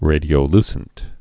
(rādē-ō-lsənt)